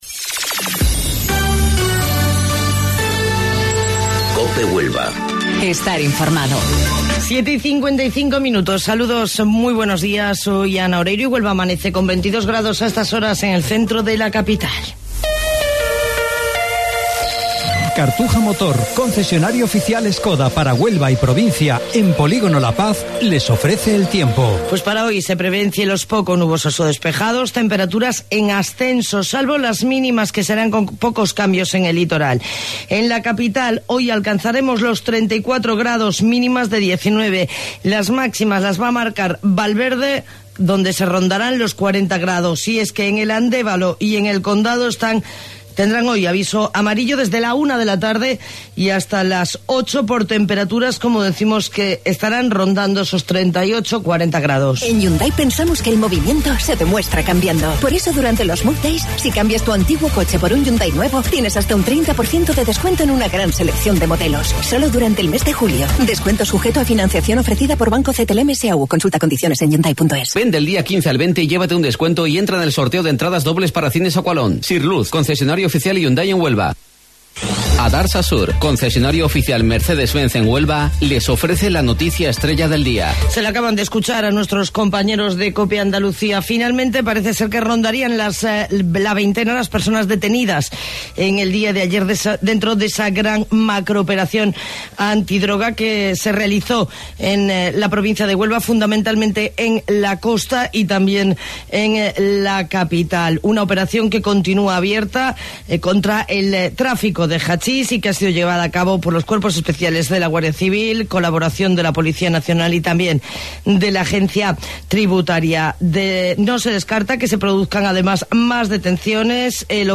AUDIO: Informativo Local 07:55 del 19 de Julio